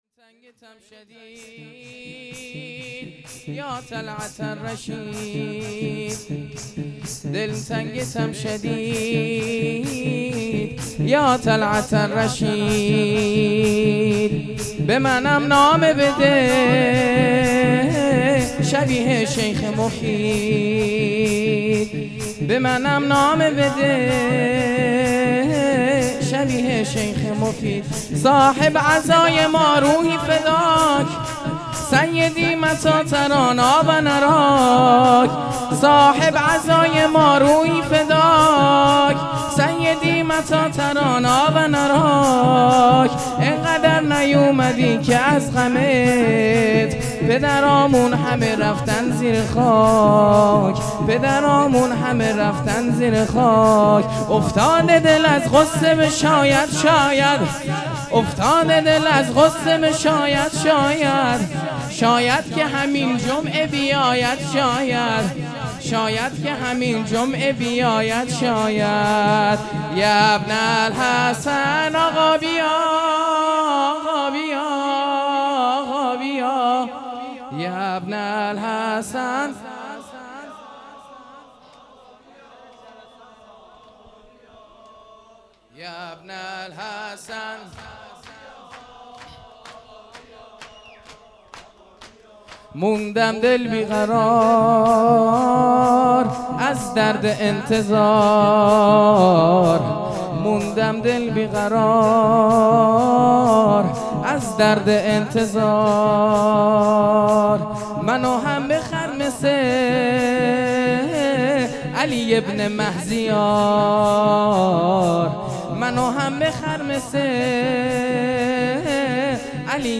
شور
شب سوم محرم